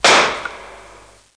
00027_Sound_gun2